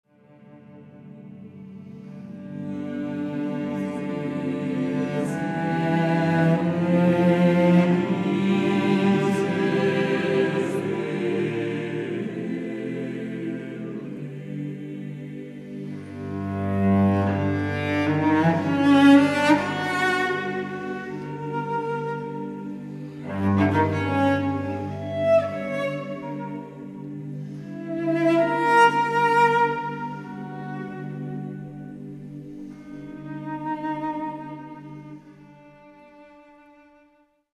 SATB (div.) (8 voices mixed).
Contemporary. Sacred.